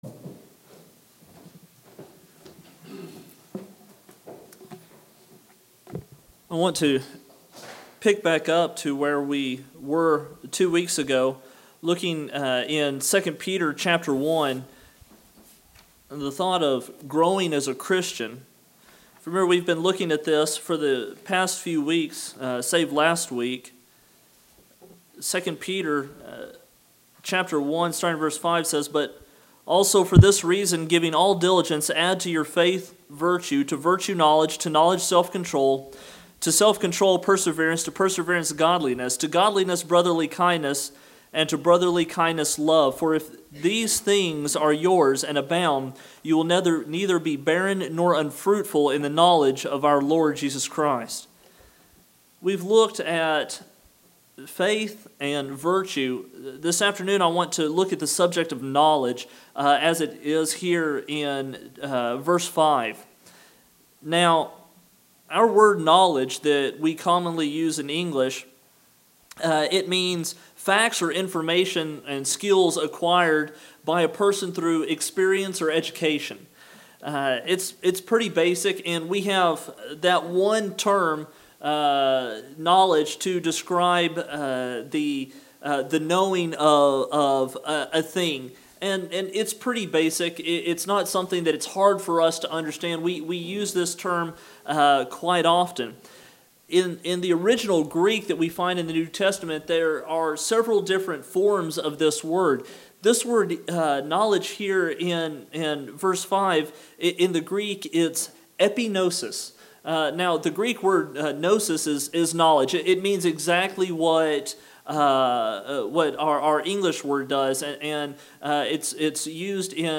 Sermon – 2nd Peter Knowledge